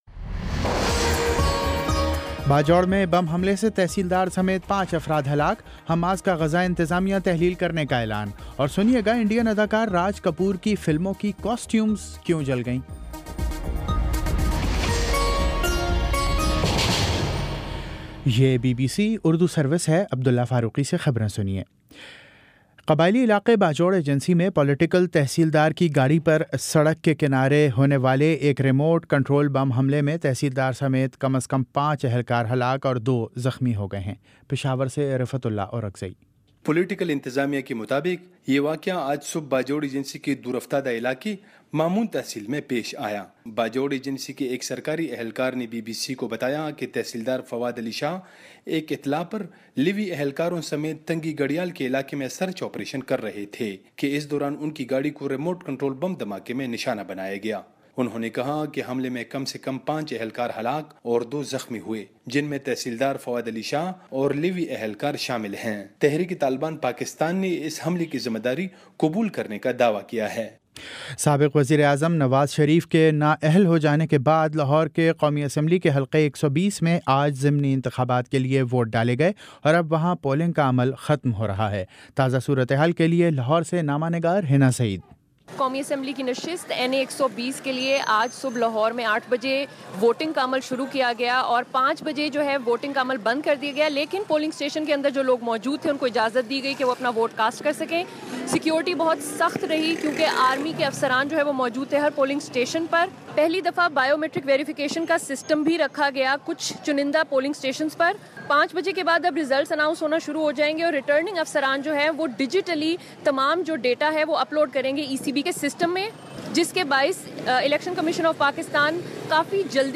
ستمبر 17 : شام پانچ بجے کا نیوز بُلیٹن